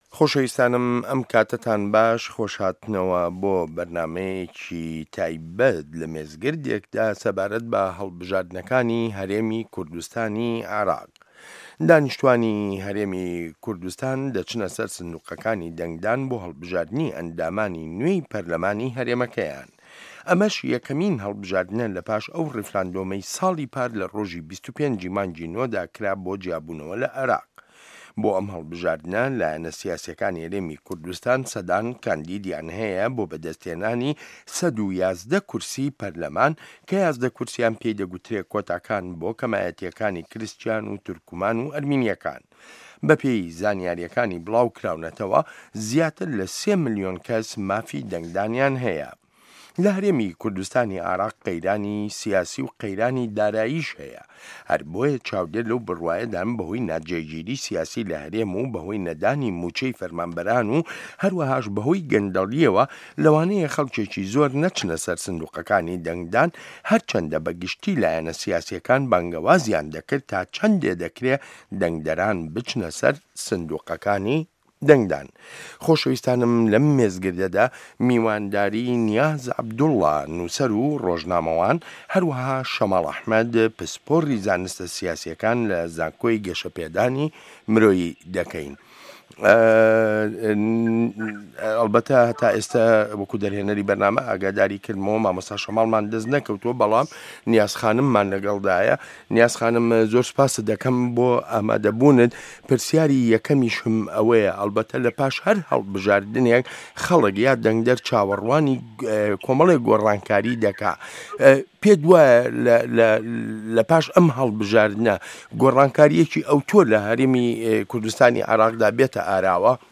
مێزگرد: هەڵبژاردنەکانی هەرێمی کوردستانی عێراق